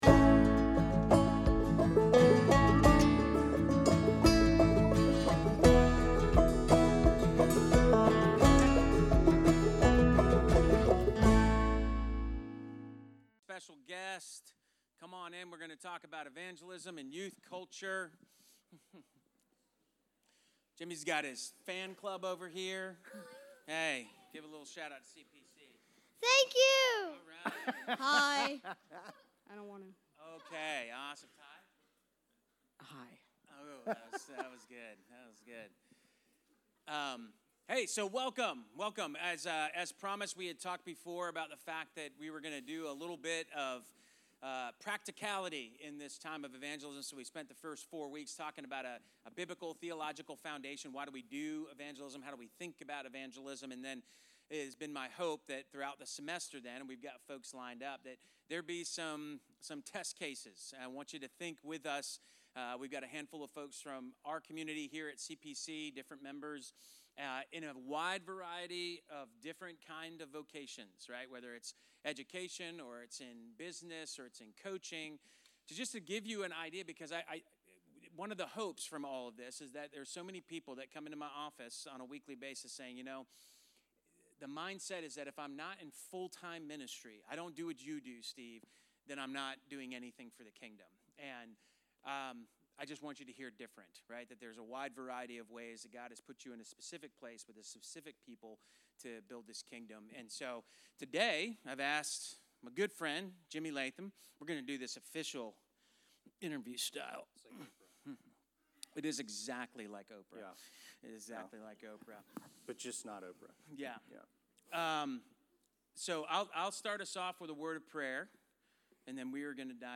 Series: Sunday School Topic: Evangelism